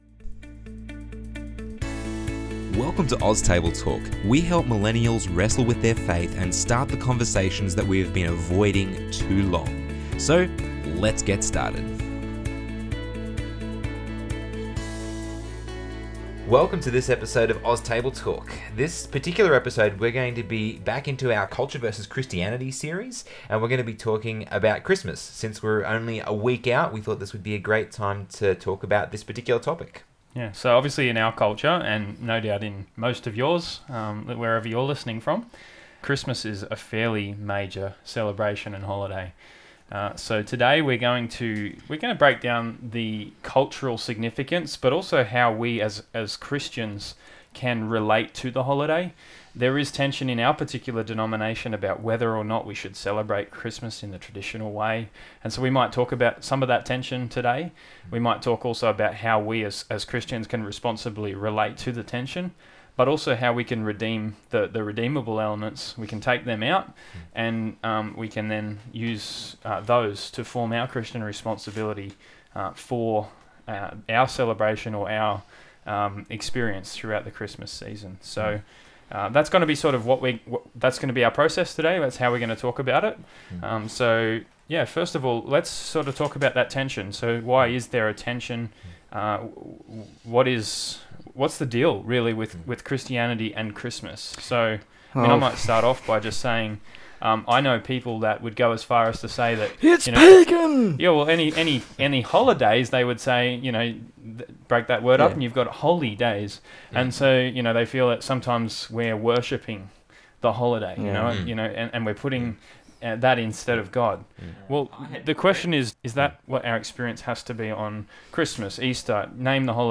Hopefully you will find this conversation refreshing, challenging and at times even a little funny.